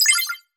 选择颜色音效.mp3